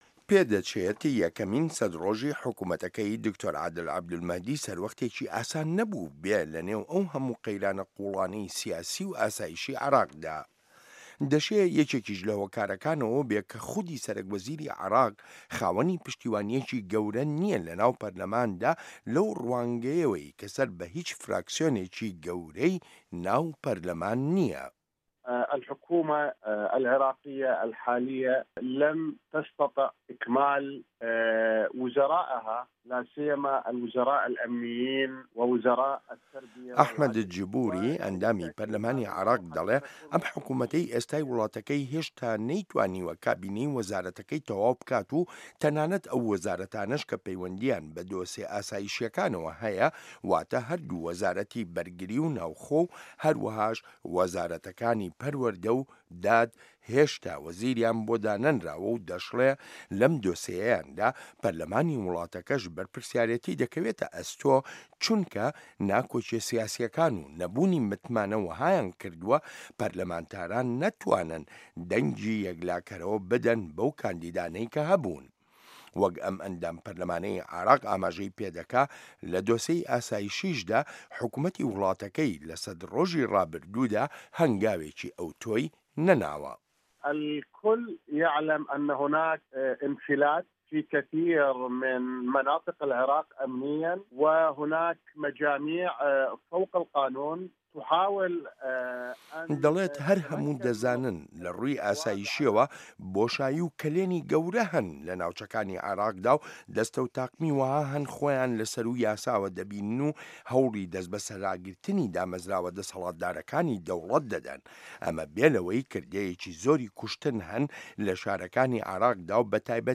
ڕاپۆرت لەسەر بنچینەی لێدوانەکانی ئەحمەد ئەلجبوری